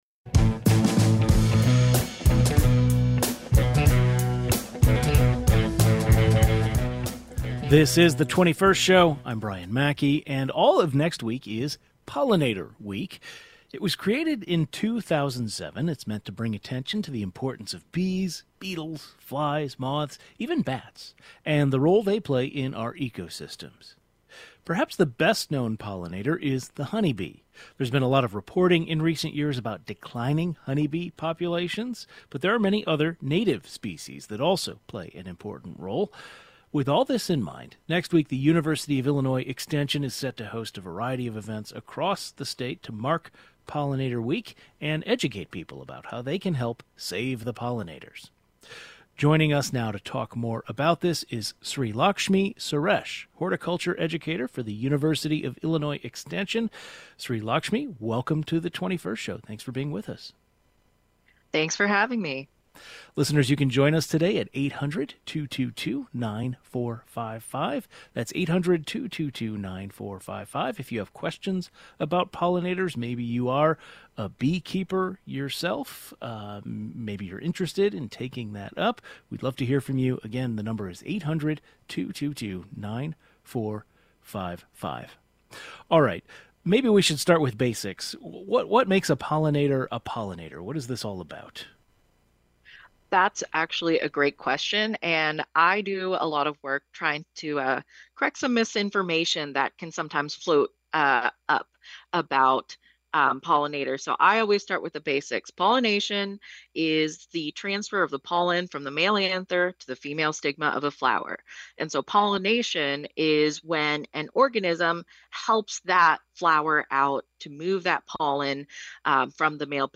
A horticulture expert joins the program to provide more information.